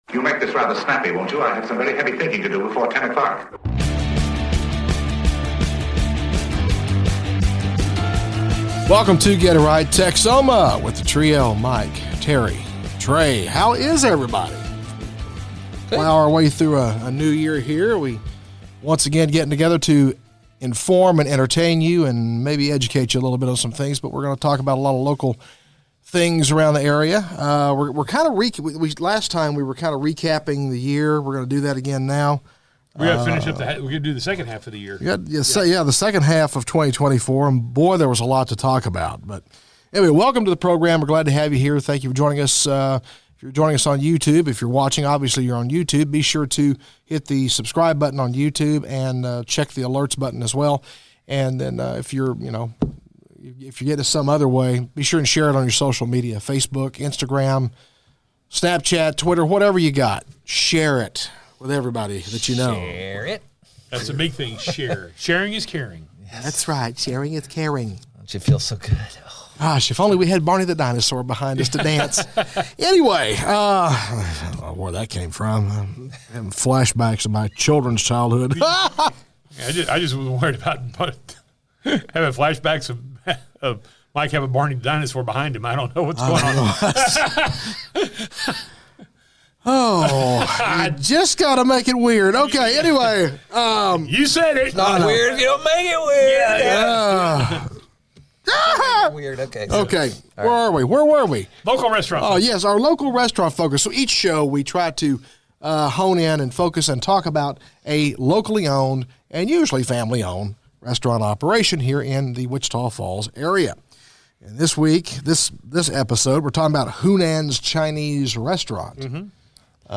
🎤 Special Guests: None this episode—but plenty of laughs and local flavor!